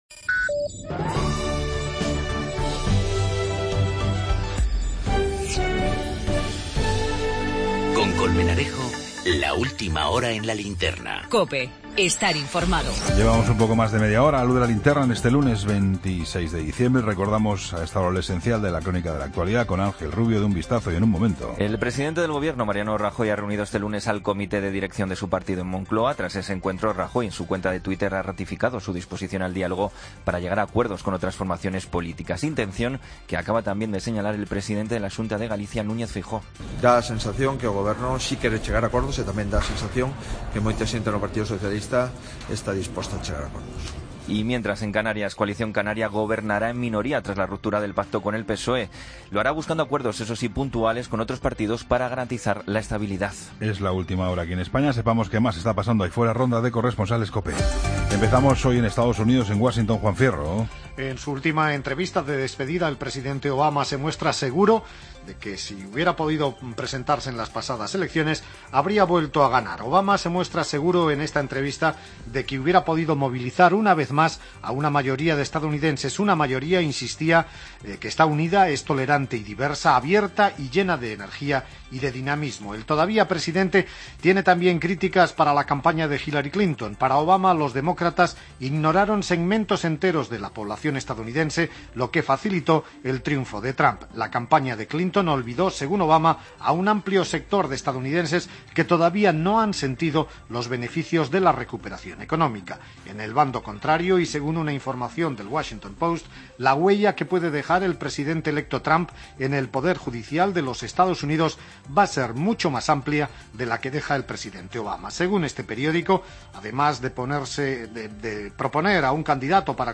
AUDIO: Toda la información con Juan Pablo Colmenarejo. Ronda de corresponsales.